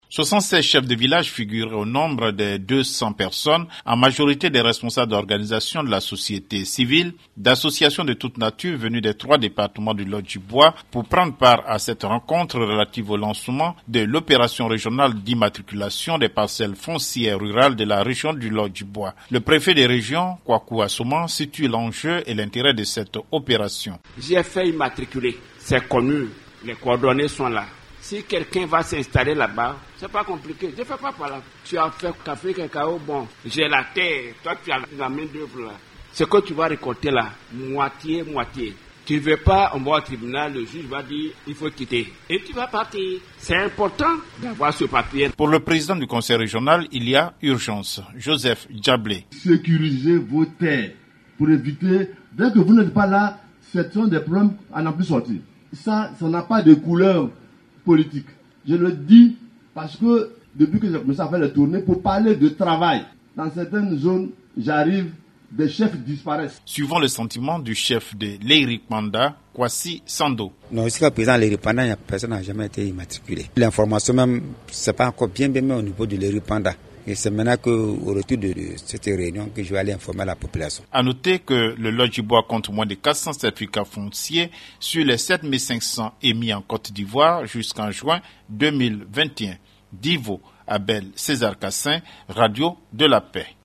Correspondance.